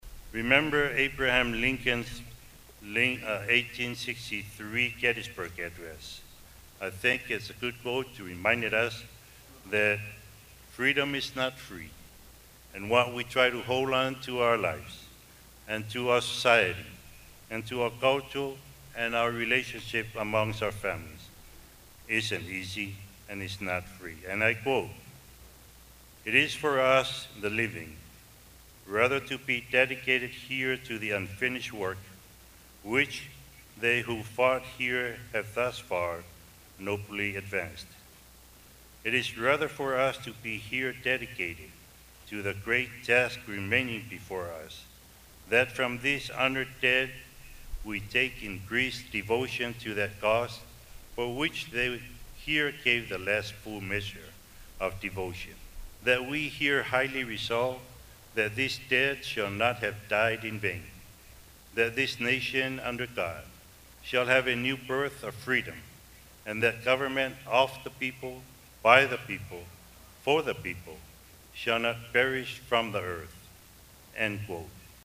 The part of the Governor’s speech which received applause was at the end, when he announced that tomorrow is an ASG holiday. Lemanu said tomorrow will be a Family Day. He said it’s not a day off for the father to get drunk or the mother to play bingo, but a holiday for the whole family.